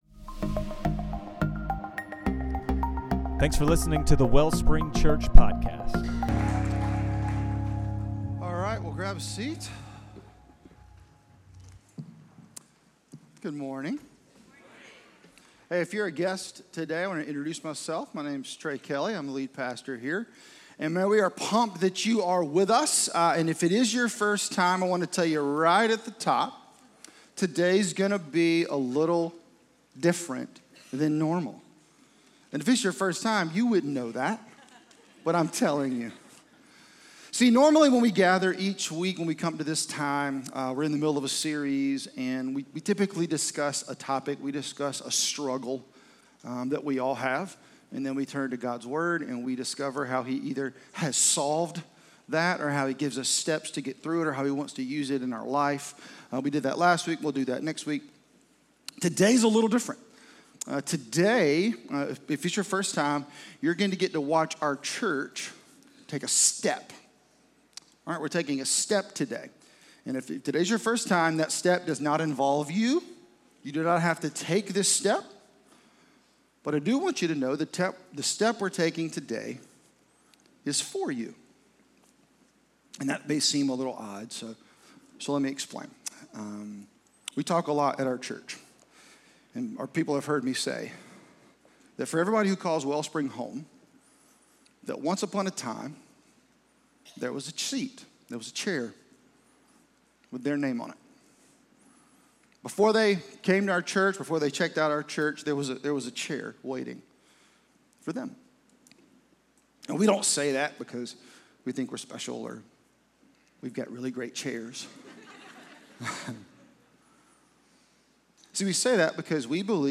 Christmas Eve 2025
Sermons